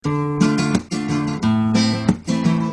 Flamenco guitars soundbank 1
Free MP3 flamenco guitars loops & sounds 1
guitar loop - Flamenco 1